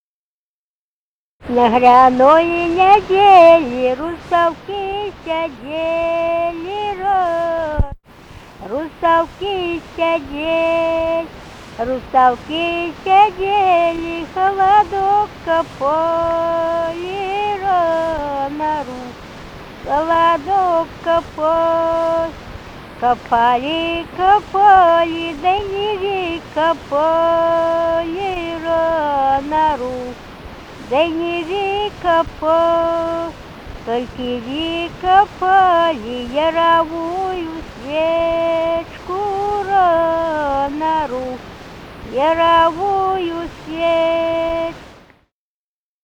Музыкальный фольклор Климовского района 034. «На гряной неделе» (гряная).
Записали участники экспедиции